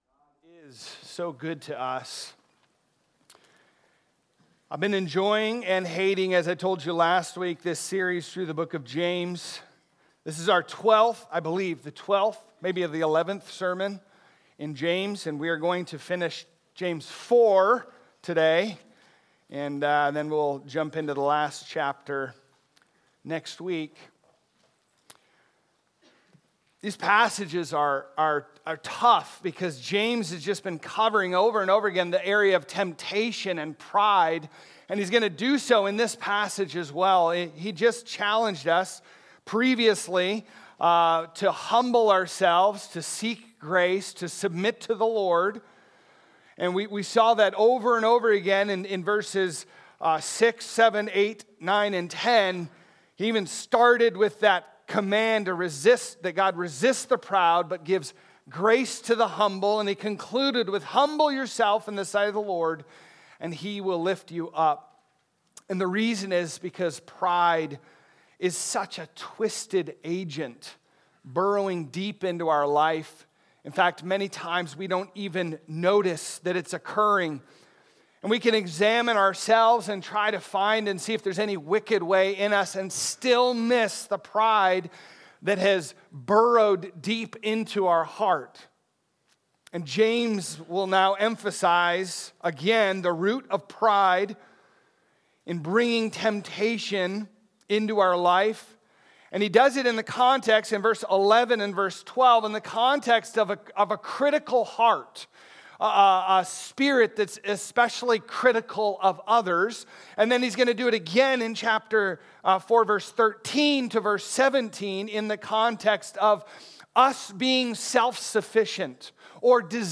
Sermons | Trinity Baptist Church